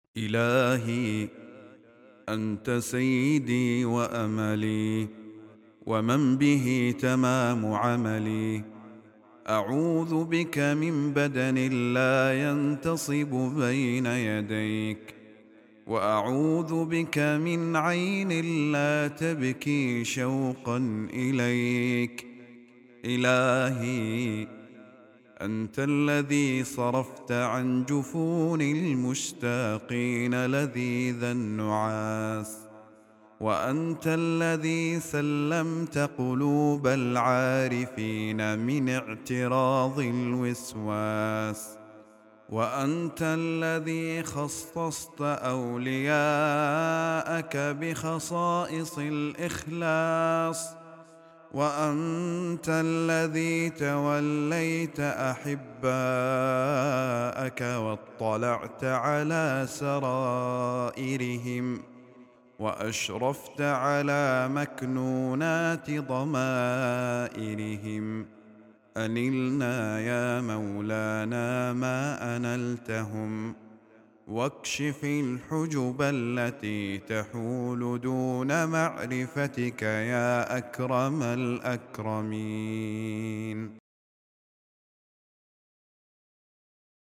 دعاء خاشع يعبر عن الالتجاء إلى الله والاستعاذة به من كل سوء، مع الاعتراف بفضله في صرف الهموم وتثبيت قلوب العارفين. النص يبرز معاني الولاية والاخلاص والشوق إلى معرفة الحق سبحانه.